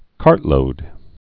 (kärtlōd)